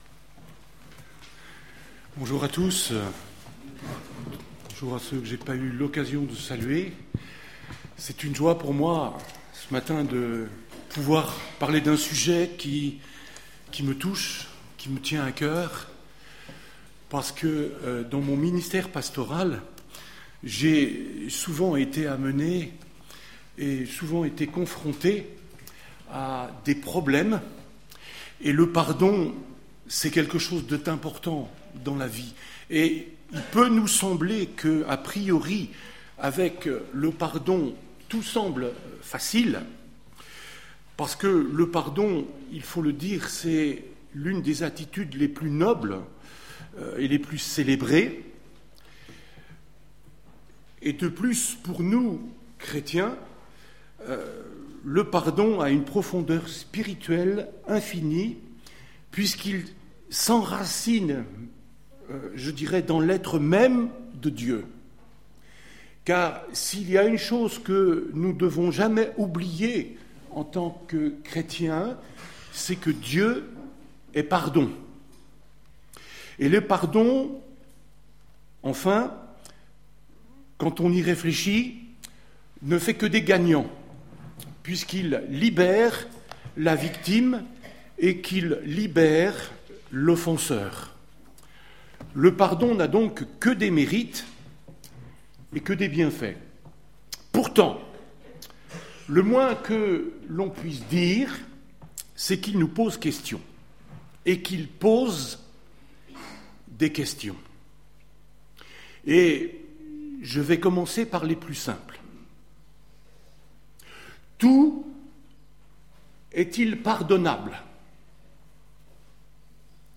Conférences Topics